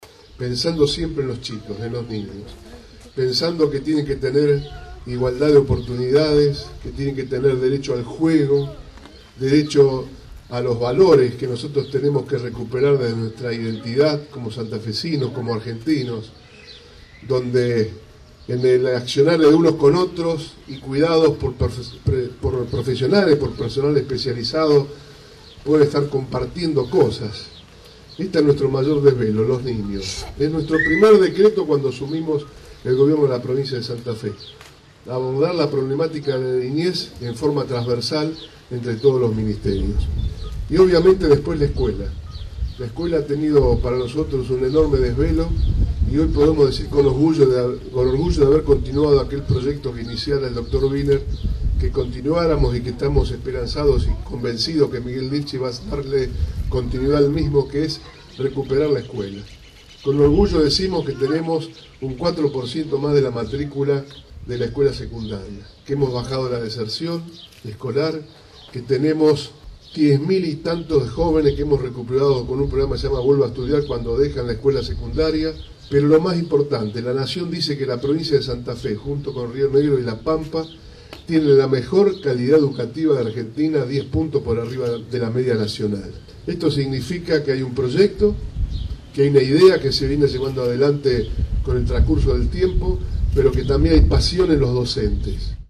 “Los niños son nuestro mayor desvelo; desde el primer día abordamos a la infancia de manera trasversal”, señaló el gobernador durante el acto desarrollado en el Centro, ubicado en la intersección de las calles Mitre y Corrientes.